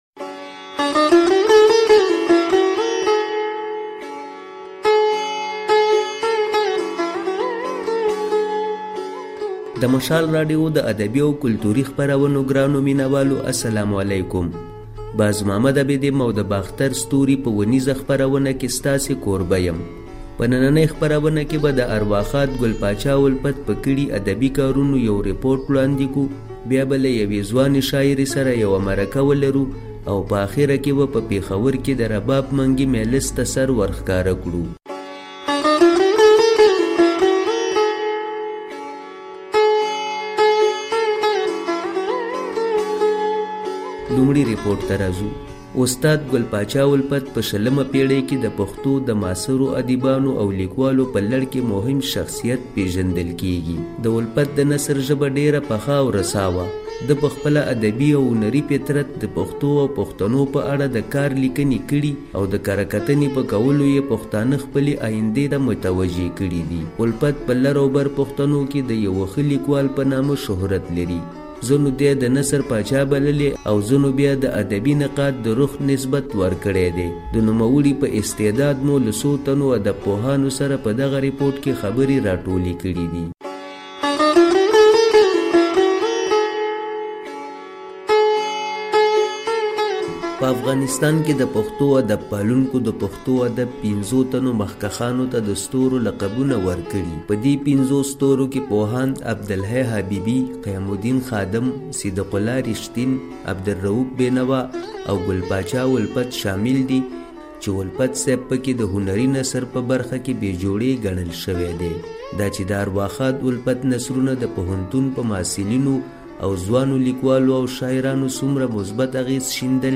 د باختر ستوري په خپرونه کې د ليکوال ګل پاچا الفت د نثر د اغيزې په اړه رپوټ، له يوې ځوانې شاعرې سره مرکه او د ټنګ ټکور د بنډار خواږه راټول شوي دي.